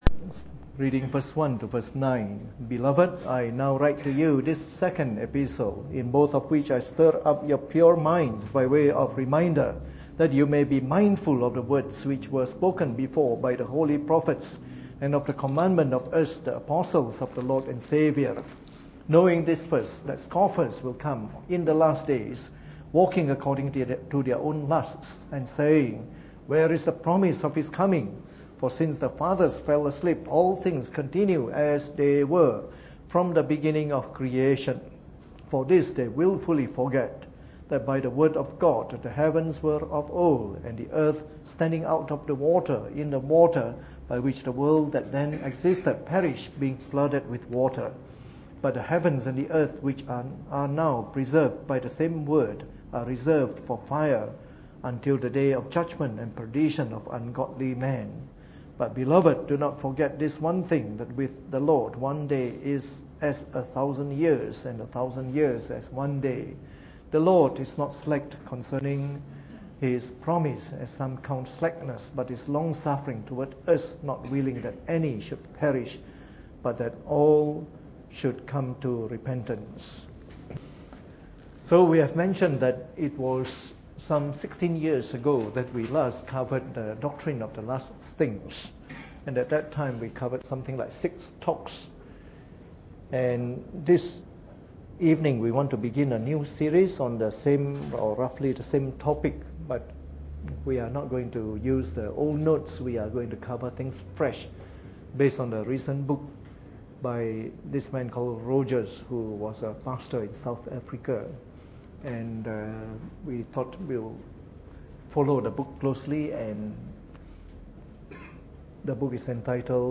Preached on the 13th of August 2014 during the Bible Study, from our new series of talks on Eschatology.